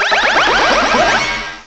cry_not_magnezone.aif